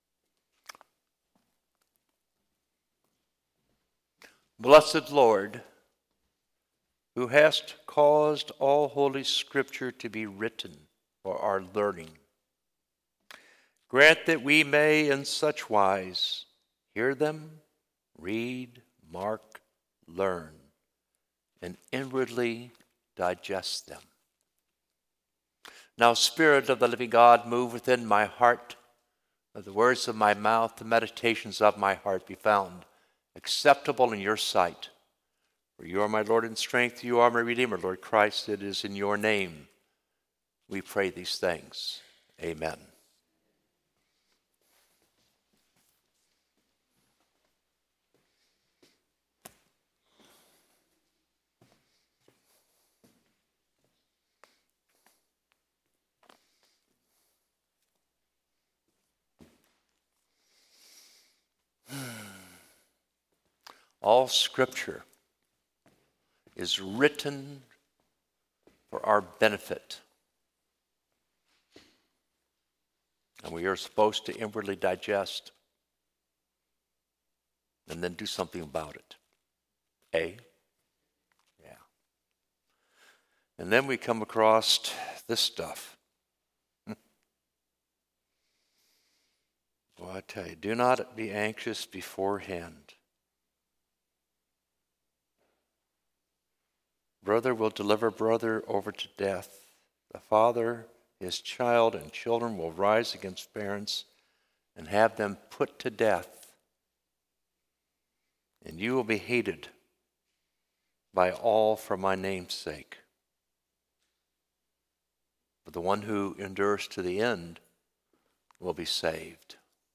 Sermon 11/17/24 Twenty-Sixth Sunday after Pentecost - Holy Innocents' Episcopal Church
Sermon 11/17/24 Twenty-Sixth Sunday after Pentecost